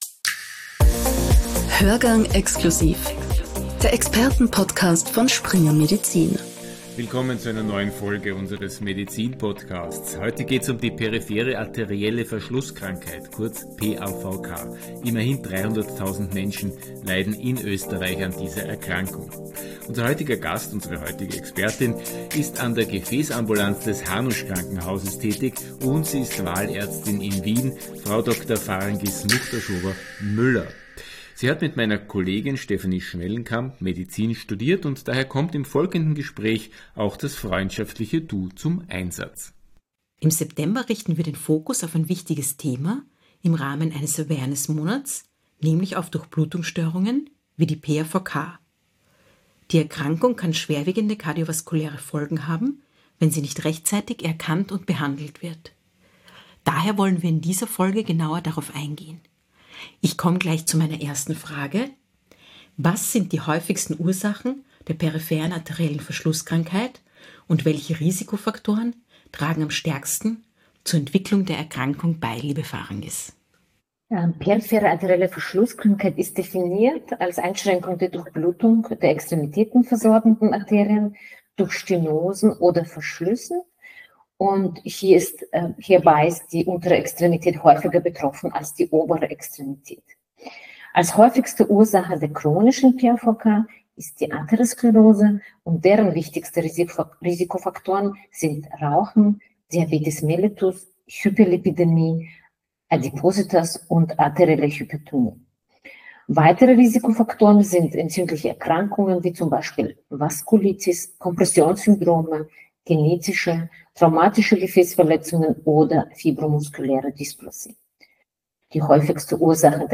Trotz der Risiken ist die Krankheit ist die Krankheit unterdiagnostiziert. Grund genug für das folgende Interview